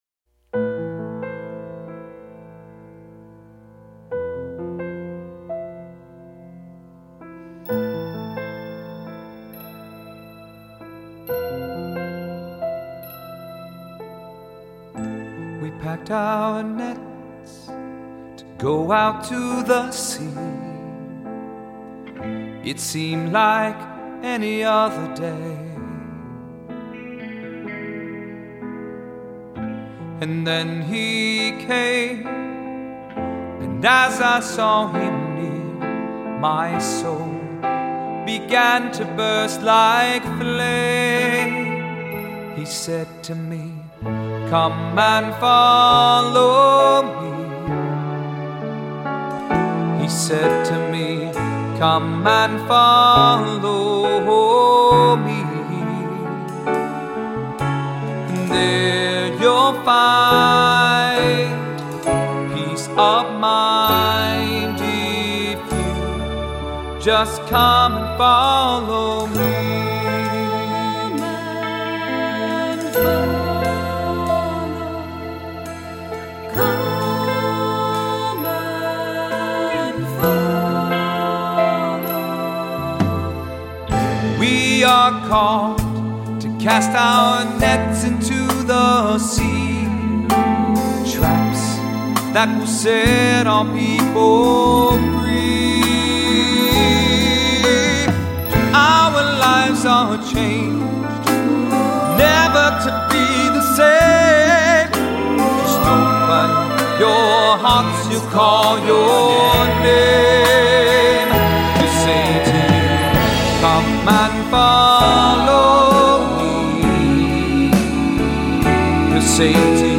Voicing: SATB; solo